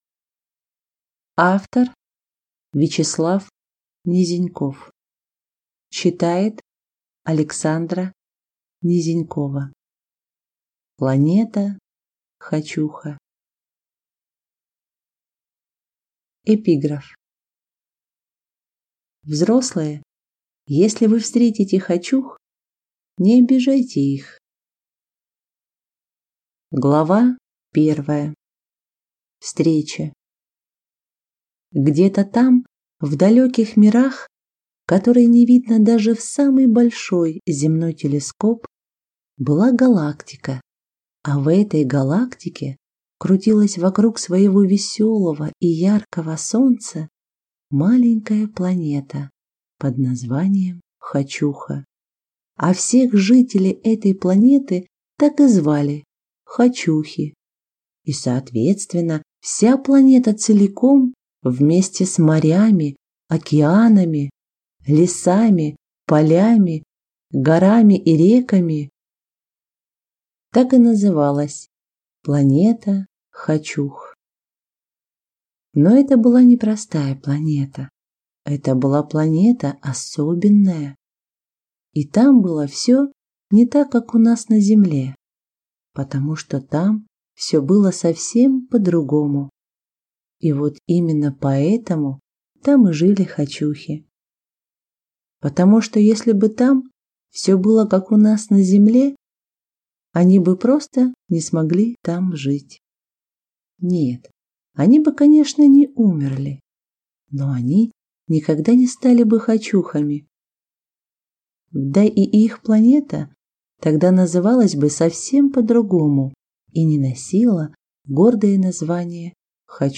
Аудиокнига Планета Хочуха | Библиотека аудиокниг